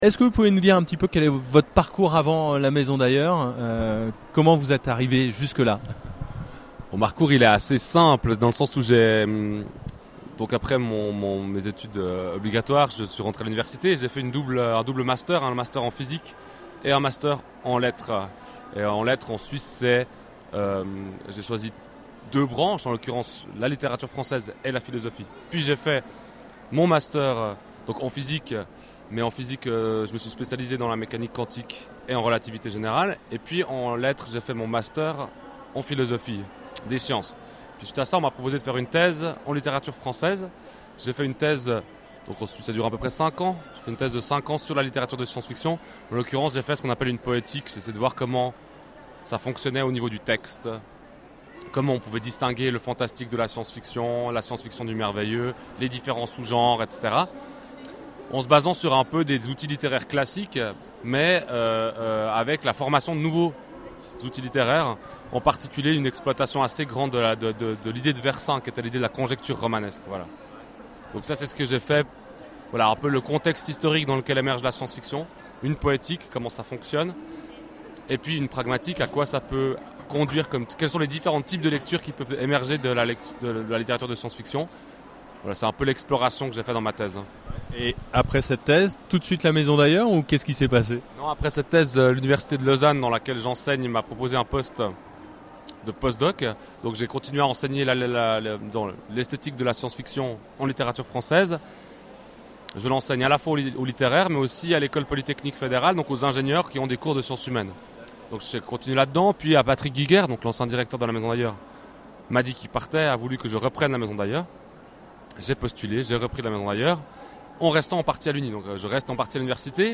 Voici l'enregistrement de l'interview.